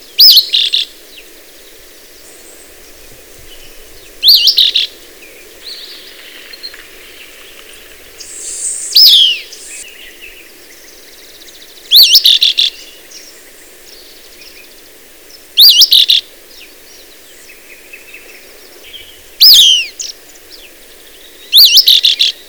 Caribbean Elaenia
Elaenia martinica
VOICE: The call consists of three whistled notes, rising in the middle; also a longer dawn song, "Peweer-peweer-peweer. " Audio
HABITS: Calls persistently in the breeding season early in the year from a perch in middle levels of the forest.